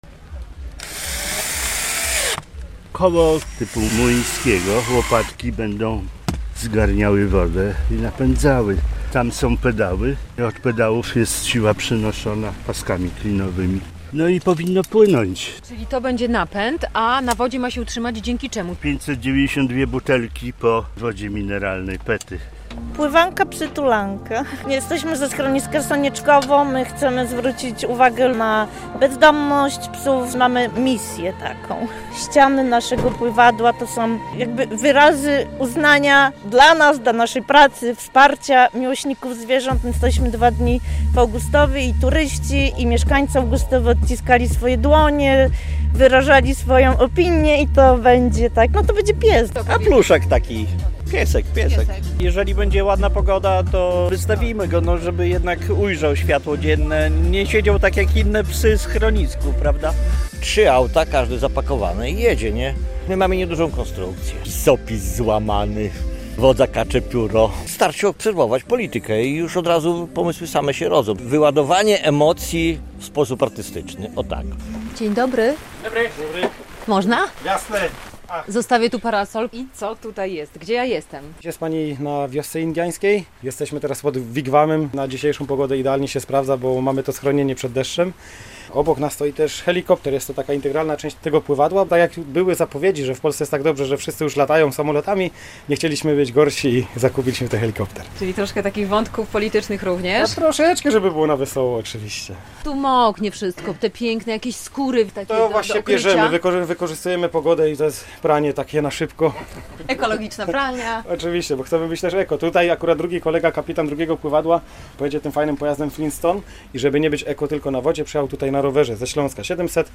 Nad rzeką Nettą trwają ostatnie przygotowania do 27. edycji Mistrzostw Polski w Pływaniu na Byle Czym - relacja